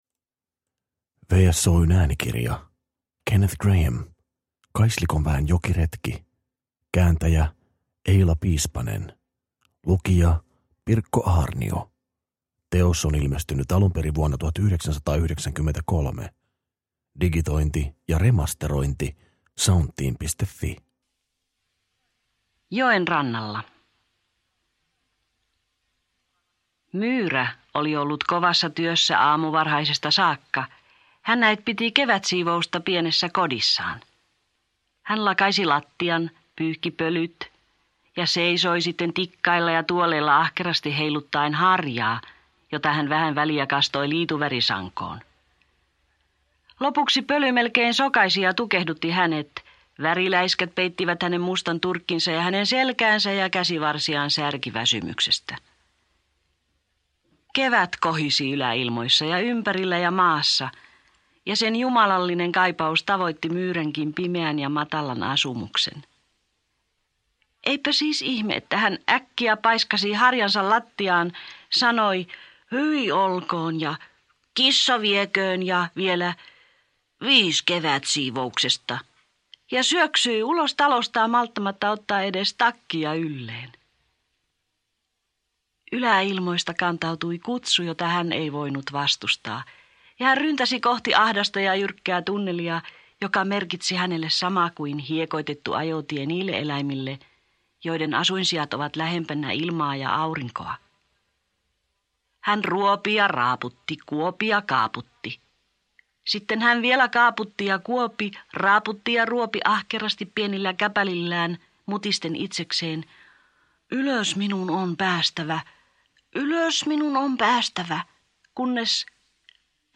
Kaislikon väen jokiretki – Ljudbok – Laddas ner